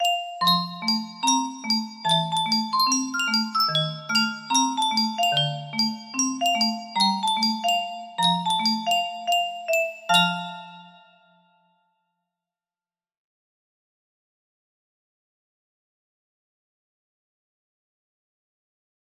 Tyrone melody music box melody
Full range 60